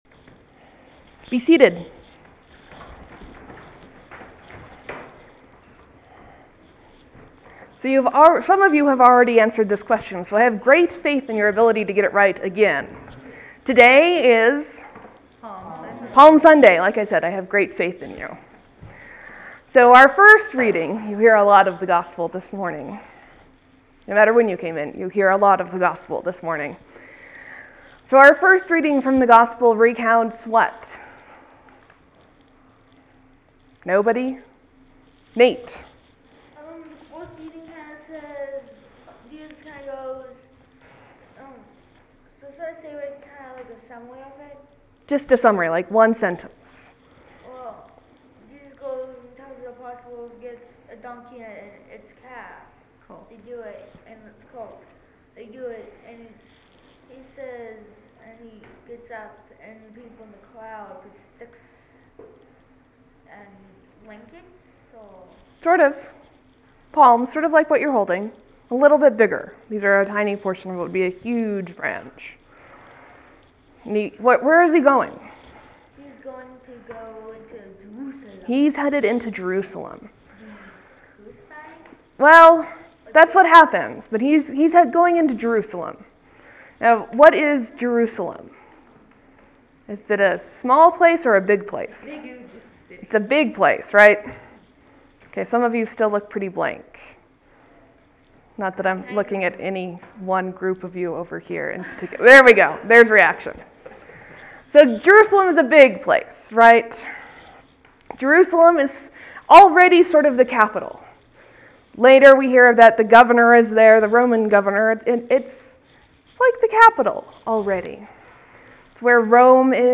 Lent, Sermon, , Leave a comment
(This sermon is interactive. I do my best to make sure that the sense of the conversation is picked up by the microphone. Thank you for your patience.)